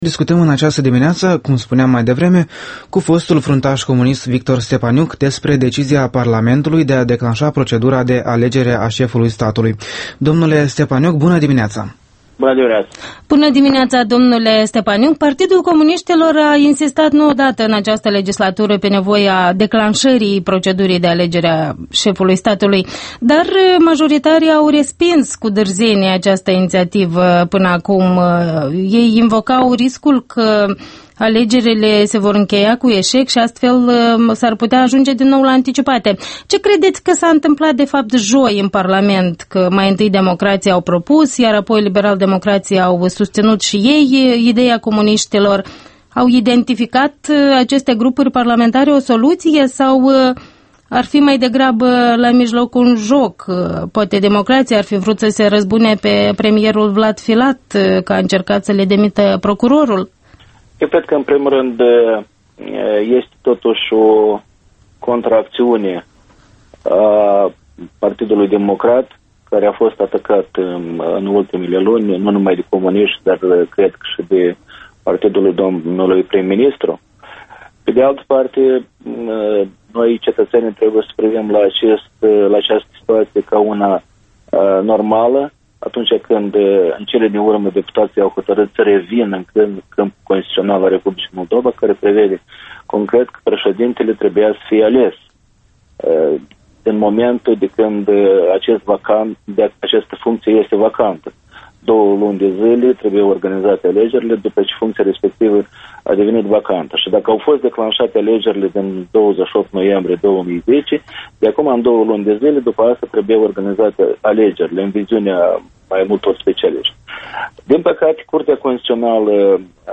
Interviul dimineții la Europa Liberă: cu Victor Stepaniuc despre alegerea președintelui țării